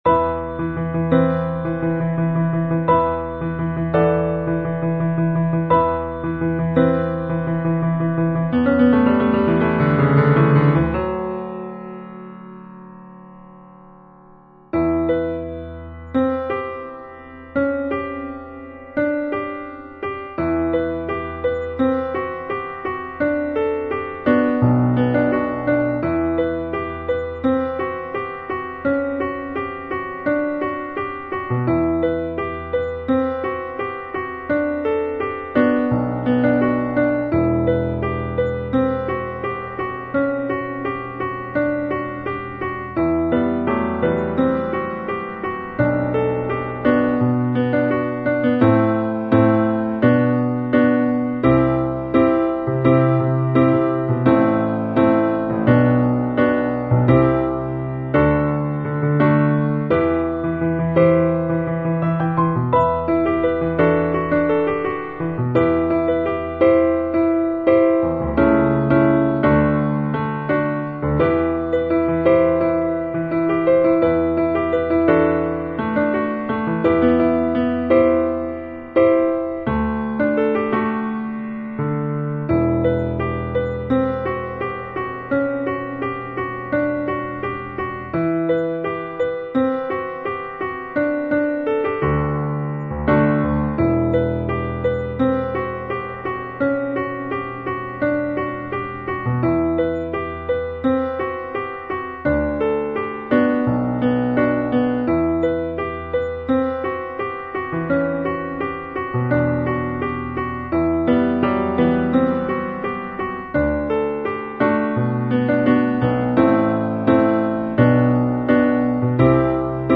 Intermediate to advanced level.